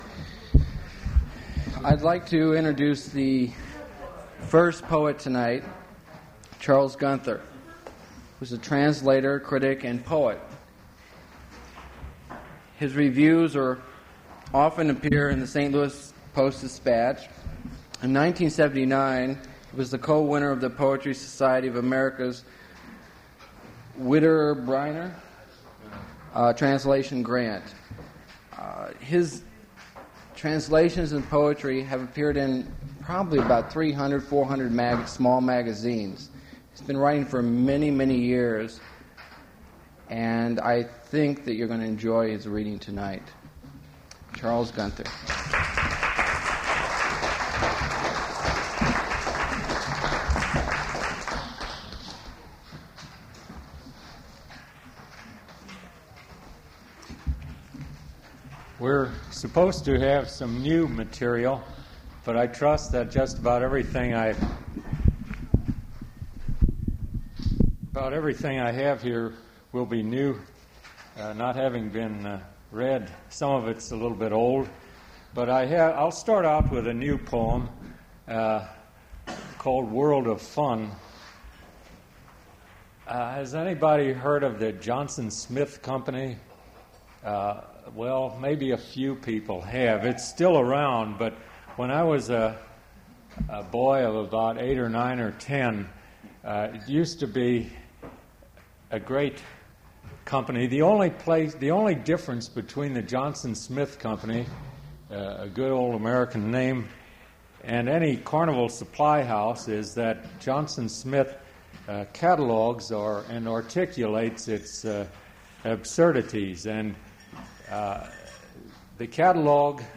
Source mp3 edited access file was created from unedited access file which was sourced from preservation WAV file that was generated from original audio cassette.
Note Poor quality at the end so cut the poems after "Cats From Bauldelaire"; some poems are translations